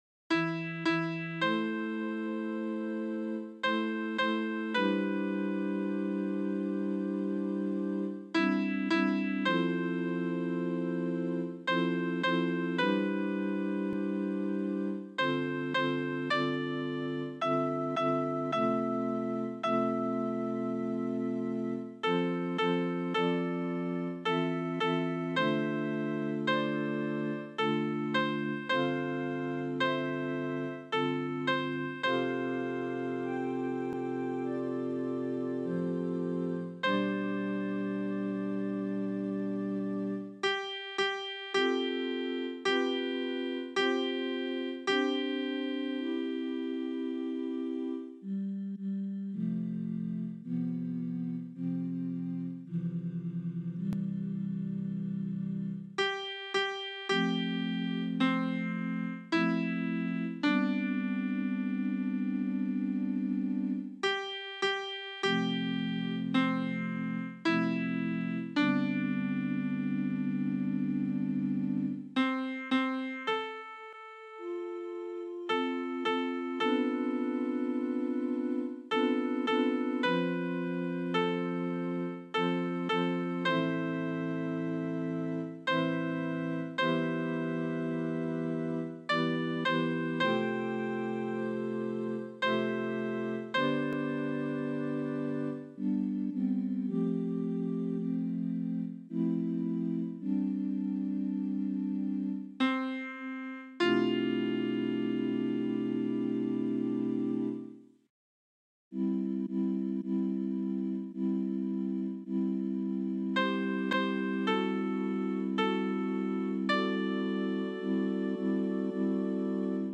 Versions piano
SOPRANO 2